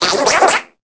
Cri de Concombaffe dans Pokémon Épée et Bouclier.